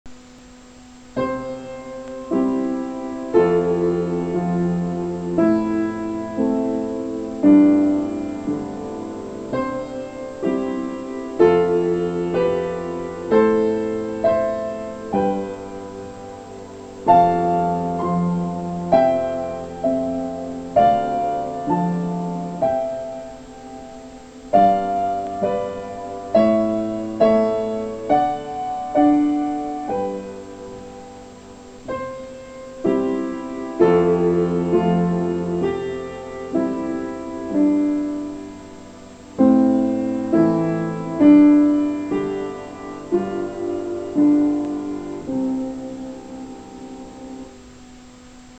Half note rhythm
blanca_oques.mp3